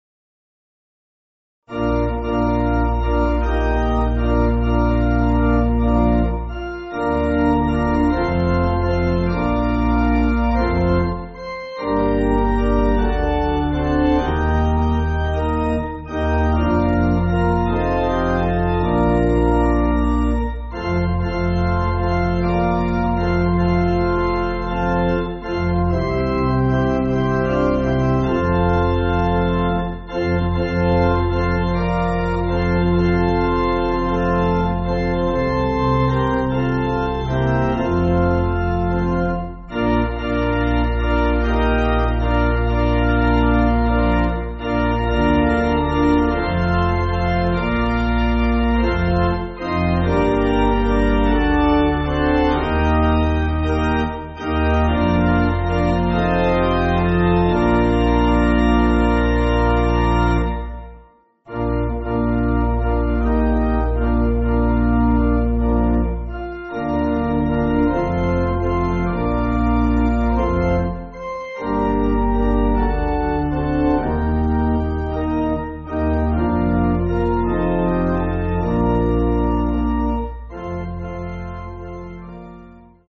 (CM)   4/Bb